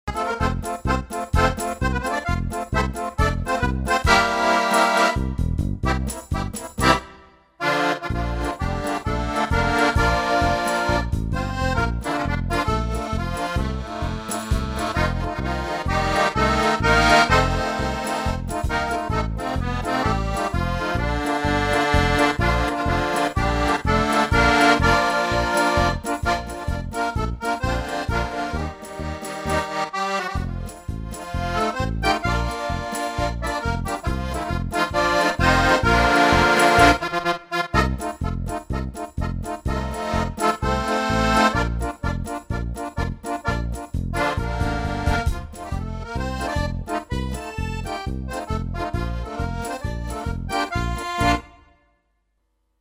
Die Aufnahmen stammen noch aus dem Jahr 2012 - aufgenommen auf meinem alten Tyros 2 Keyboard von Yamaha nach dem Motto: